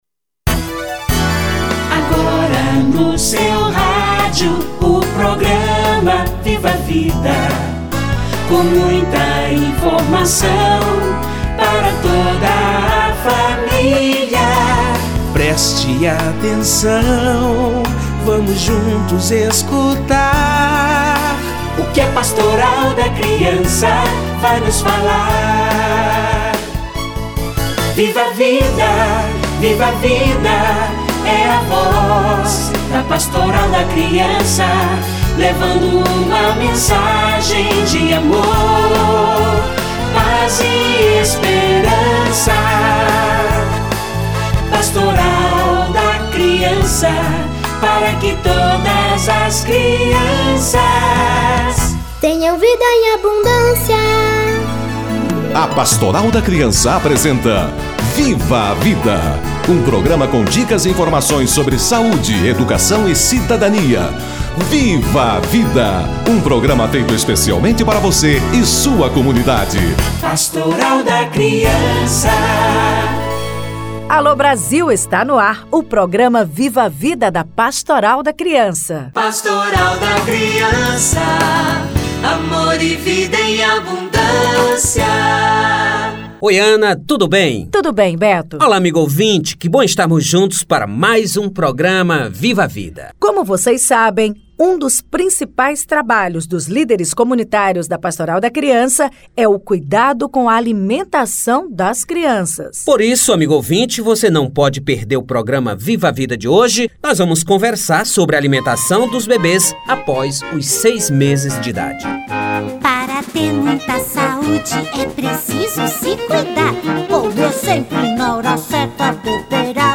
Alimentação dos bebês - Entrevista